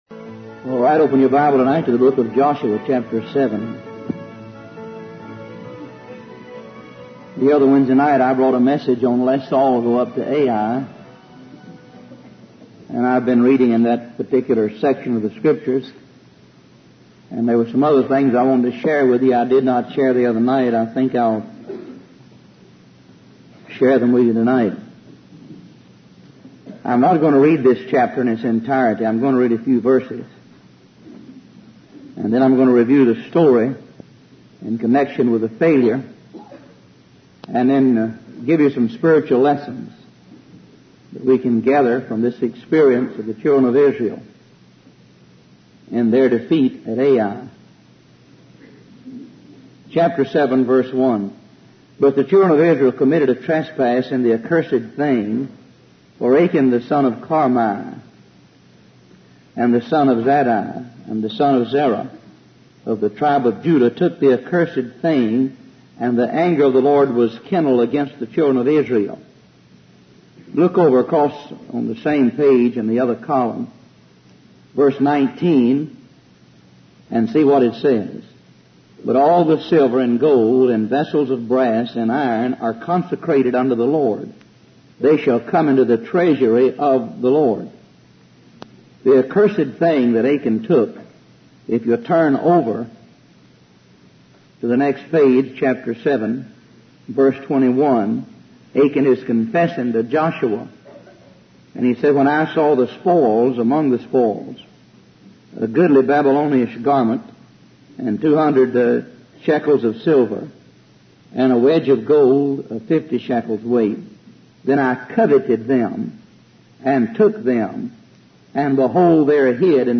Professing themselves to be wise, they became fools. Wise Desire Ministries helps convey various Christian videos and audio sermons.